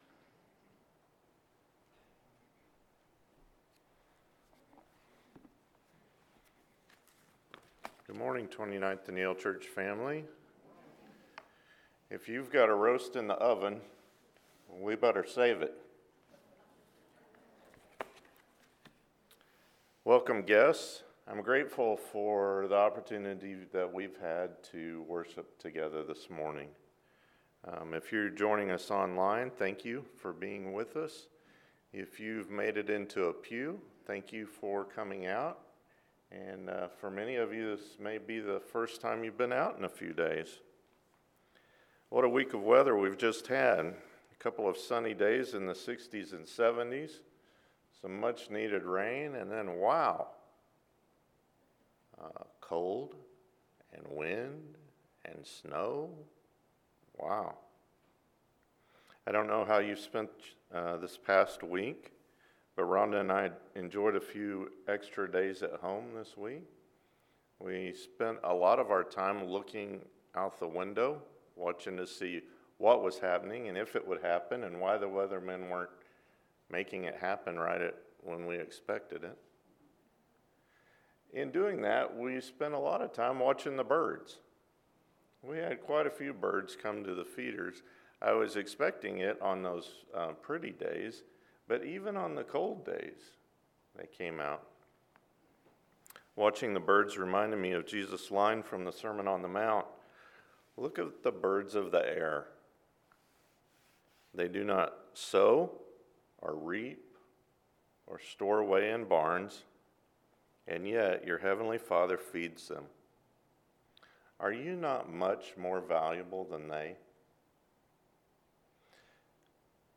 Kingdom Stories – Persistent Widow – Pharisee and Tax Collector – Sermon